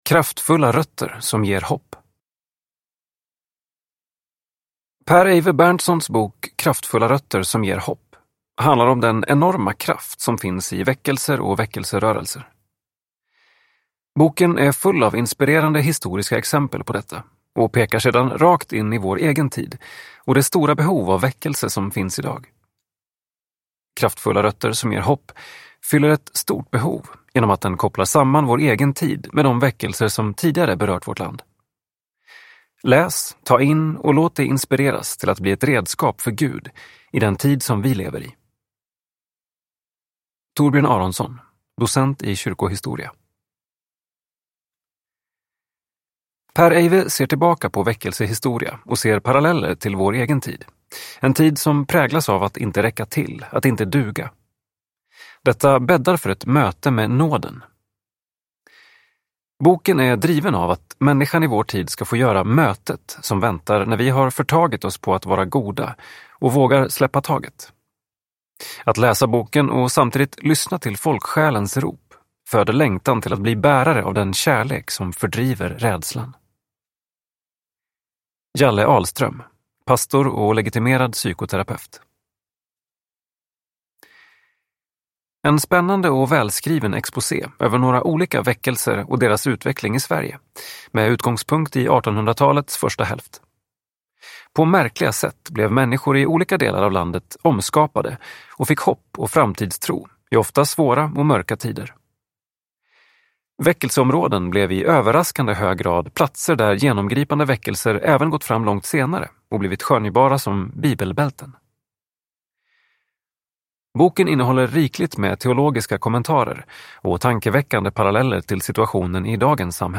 Kraftfulla rötter som ger hopp – Ljudbok